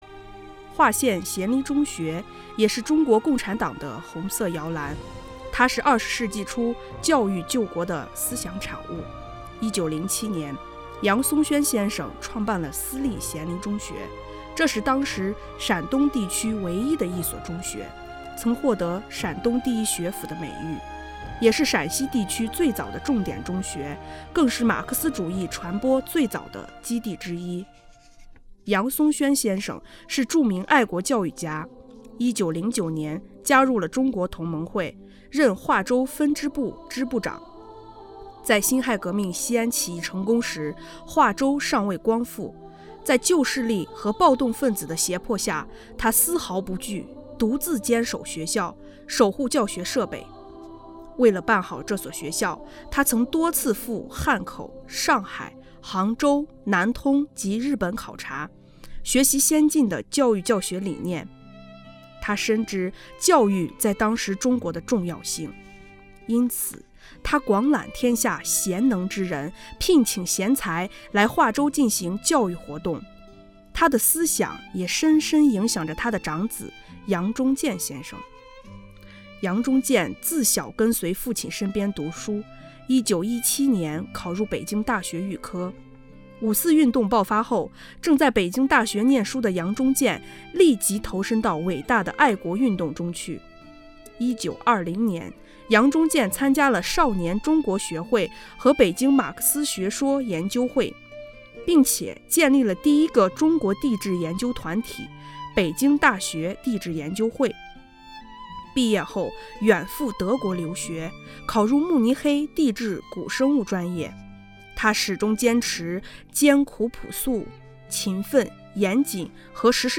【红色档案诵读展播】马克思主义在渭南传播的早期阵地——咸林中学